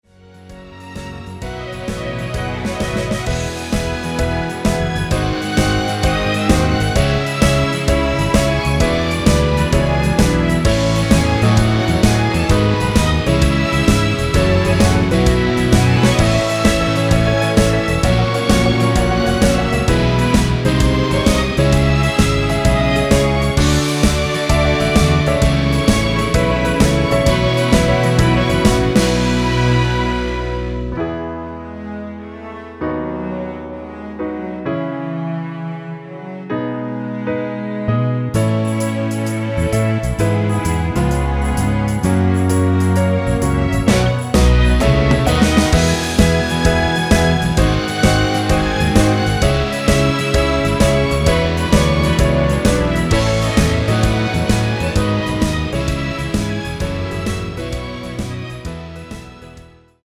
전주 없는 곡이라 전주 2마디 만들어 놓았습니다.
엔딩이 페이드 아웃이라 라이브 하시기 편하게 엔딩을 만들어 놓았습니다
1절후 2절 없이 후렴으로 진행 됩니다.(본문 가사 참조)
Db
앞부분30초, 뒷부분30초씩 편집해서 올려 드리고 있습니다.